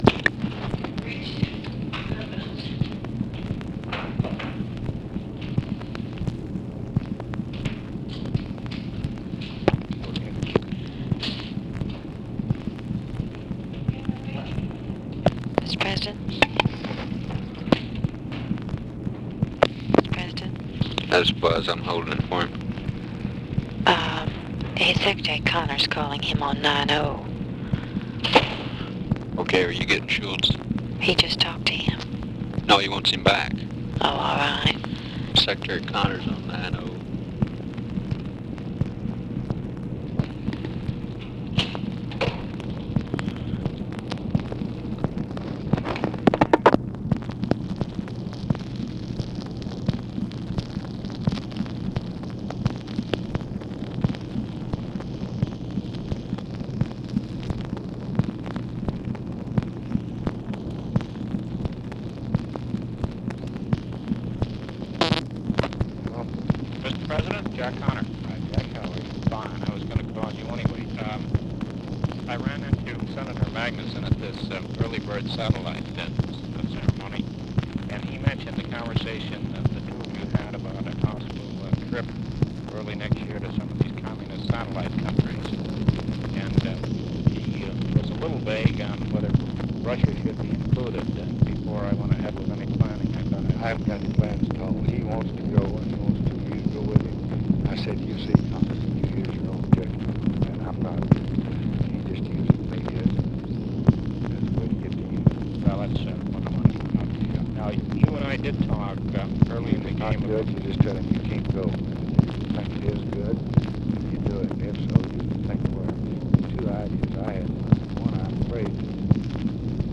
Conversation with JOHN CONNOR, OFFICE SECRETARY and HORACE BUSBY, June 28, 1965
Secret White House Tapes